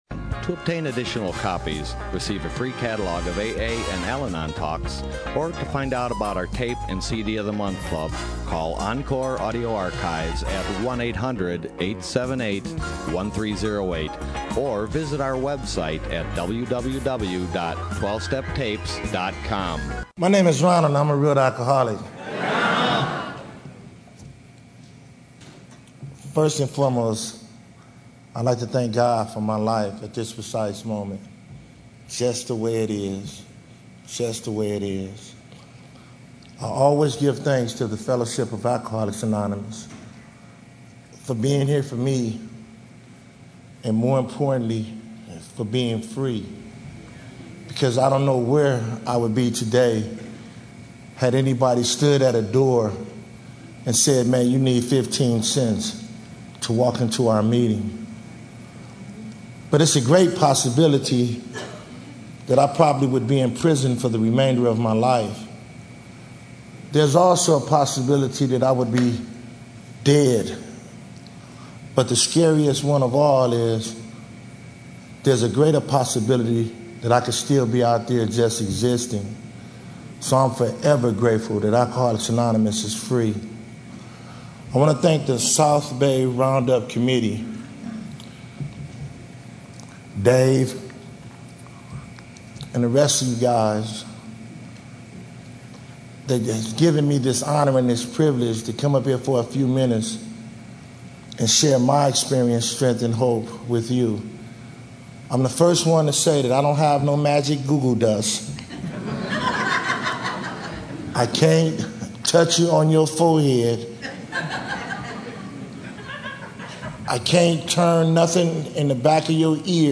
SOUTHBAY ROUNDUP 2013